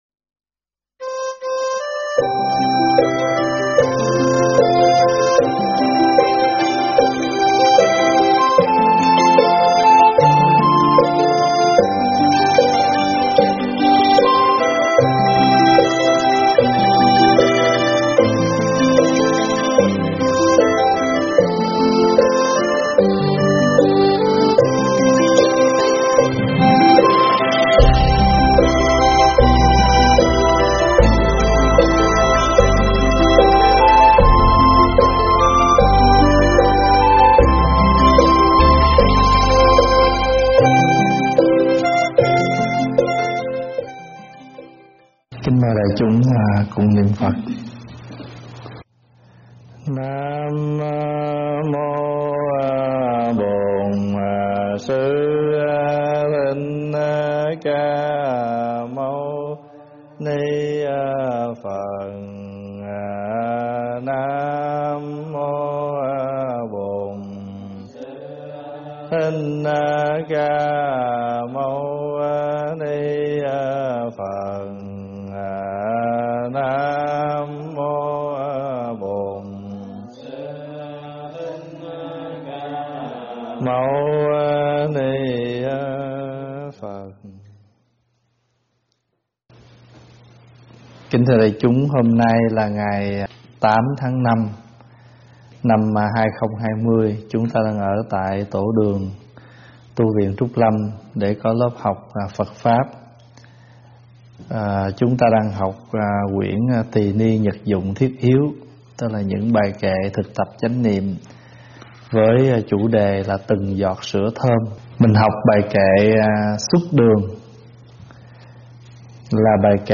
thuyết pháp
giảng tại Tv Trúc Lâm